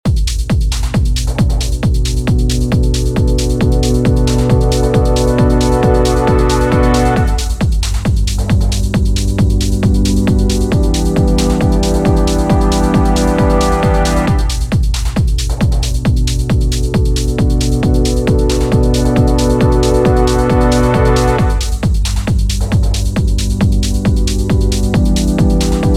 As we’re working in E Minor the note to the left of B is A. For G it is F# and for E it is D. Add all of these notes to the chords to turn them into 7th chords!